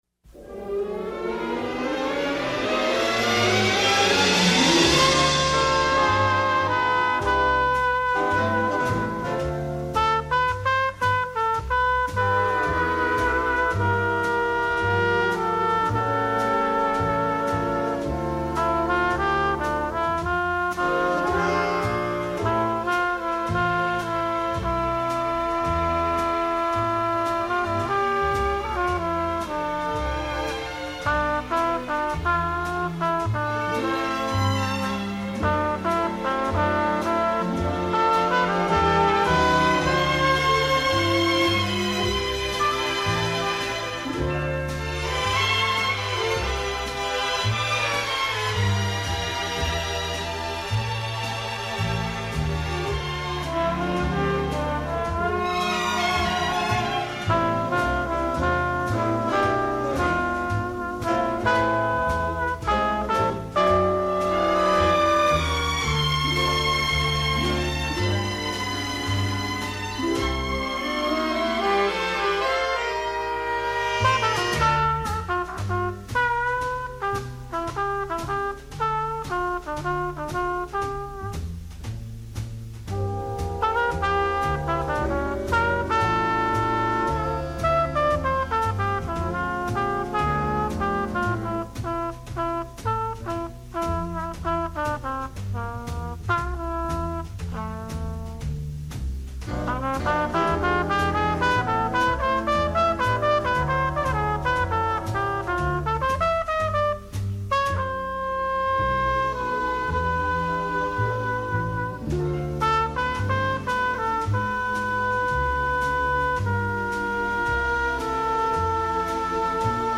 Jazz, Cool Jazz, Vocal Jazz